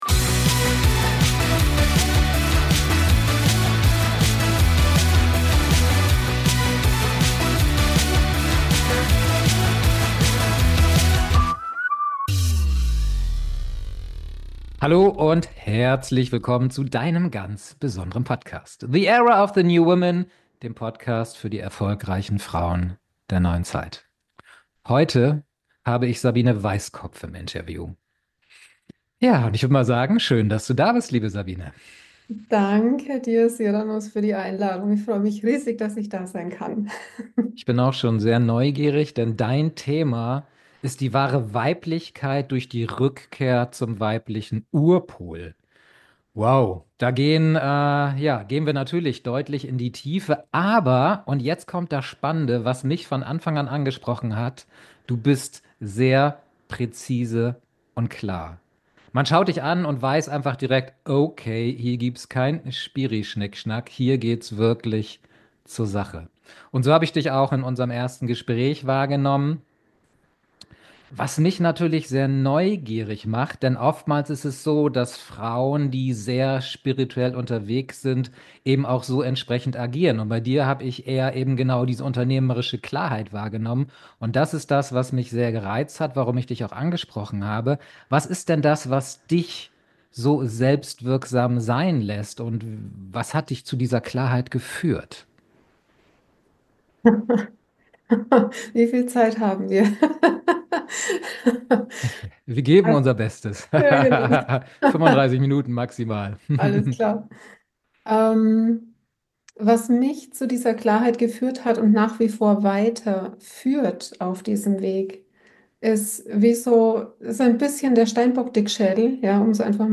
Ein Gespräch für Frauen, die innerlich klar, selbstwirksam und radikal ehrlic...